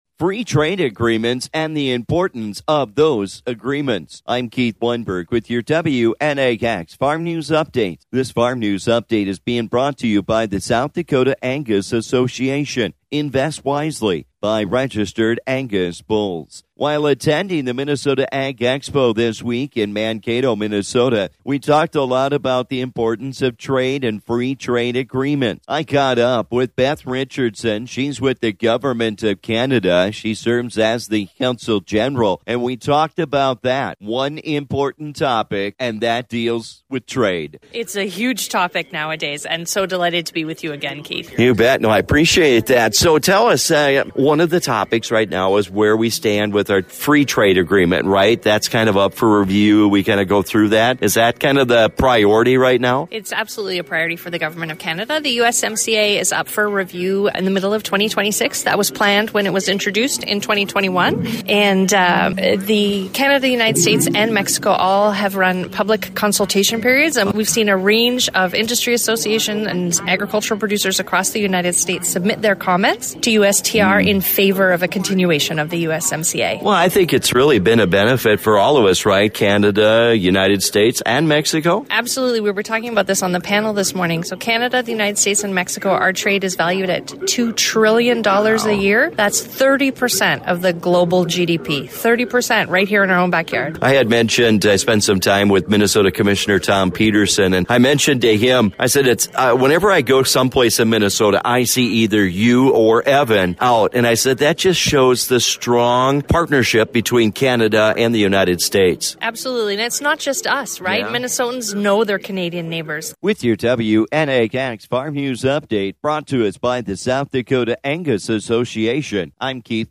Trade remains a big factor and today I talk with the Government of Canada, about just how important it is with our trading partners.